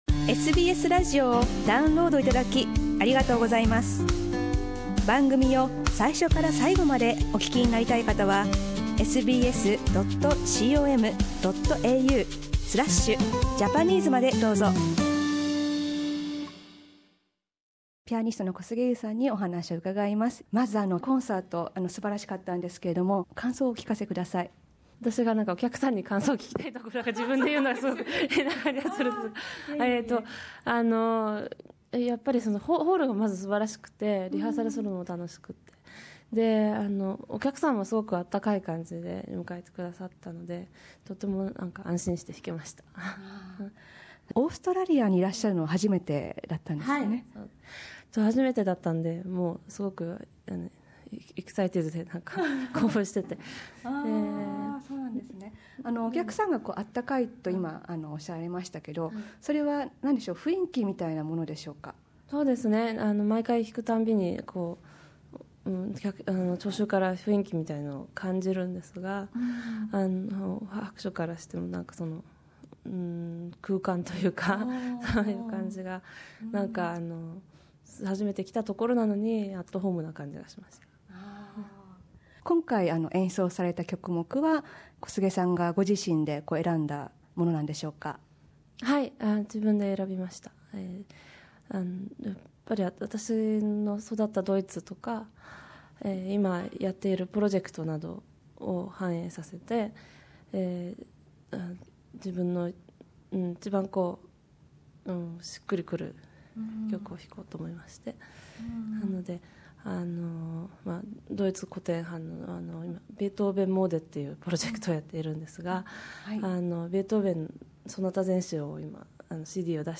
シドニーでのコンサートの翌日に、コンサートの 感想や、楽曲への取り組み方などを聞きました。10歳からドイツに留学し、海外で自らを磨いた小菅さんは、海外で夢を追う日本人にエールを送ります。